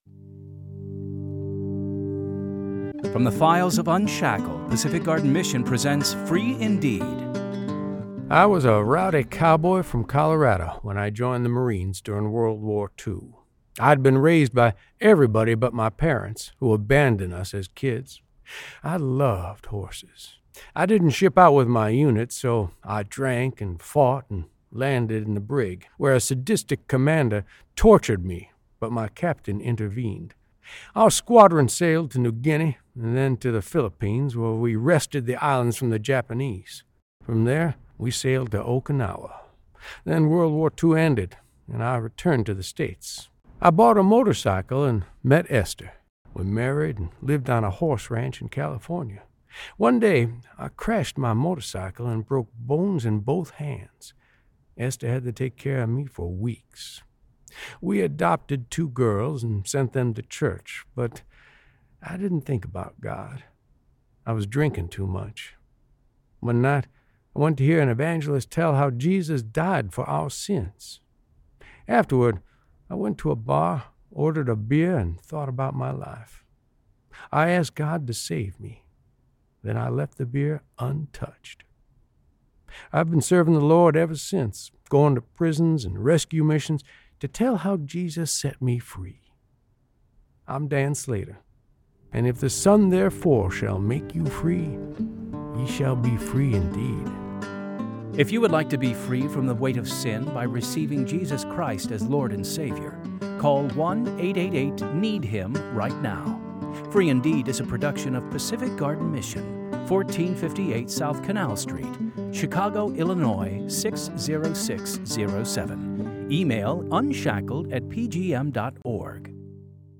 Two Minute Testimonies of True Life Stories
Free Indeed! stories are created from the files of UNSHACKLED! Radio Dramas, and produced by Pacific Garden Mission.